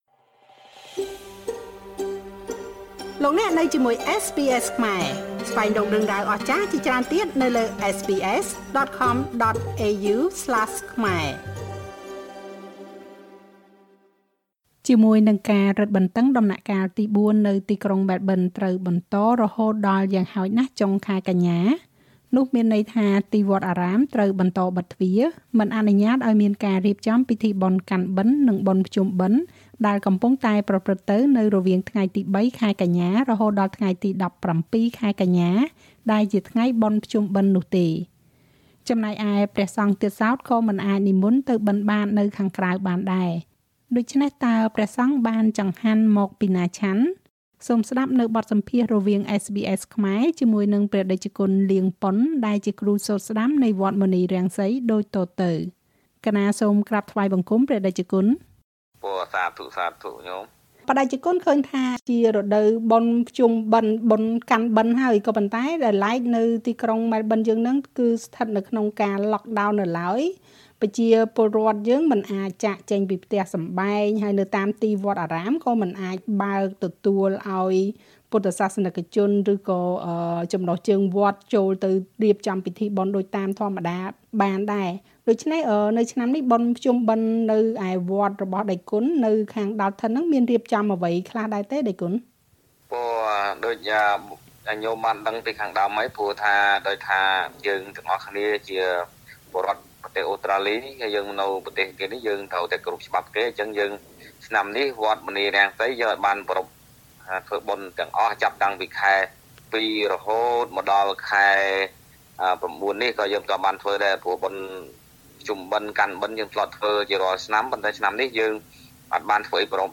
ជាមួយនឹងការរឹតបន្តឹងដំណាក់កាលទីបួននៅក្នុងក្រុងម៉ែលប៊ន ត្រូវបន្តរហូតដល់យ៉ាងហោចណាស់ចុងខែកញ្ញា មានន័យថា ទីវត្តអារាមត្រូវបិទទ្វារ មិនអនុញ្ញាតិឲ្យមានការរៀបចំពិធីបុណ្យកាន់បិណ្ឌ និងបុណ្យភ្ជំបិណ្ឌដែលកំពុងតែប្រព្រឹត្តិទៅនៅរវាងថ្ងៃទី 3 កញ្ញា រហូតដល់ថ្ងៃទី១៧កញ្ញា ដែលជាថ្ងៃភ្ជុំបិណ្ឌនោះទេ។ ព្រះសង្ឃទៀតសោតក៏មិនអាចនិមន្តទៅបិណ្ឌបាតនៅខាងក្រៅបាន?តើព្រះសង្ឃបានចង្ហាន់មកពីណាឆាន់? សូមស្តាប់បទសម្ភាសន៍រវាងSBS ខ្មែរ